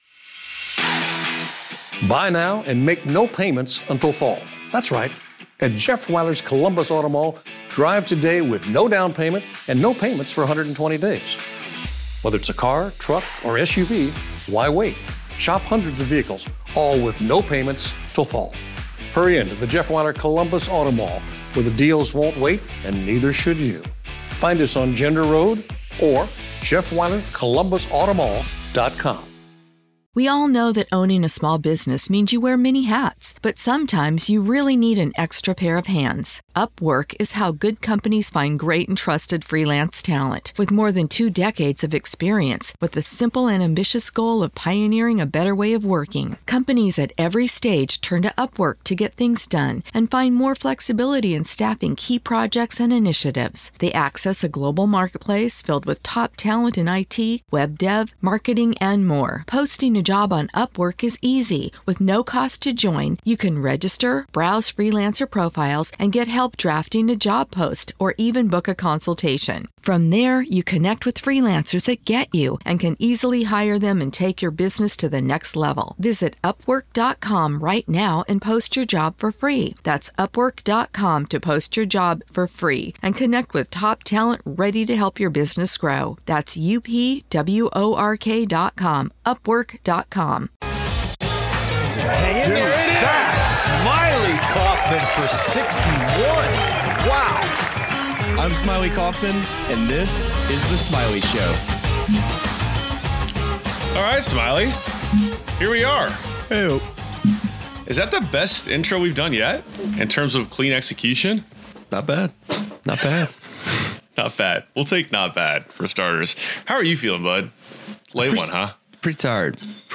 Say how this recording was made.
LIVE SHOW: Arnold Palmer Invitational Recap + PLAYERS Preview